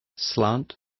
Complete with pronunciation of the translation of slant.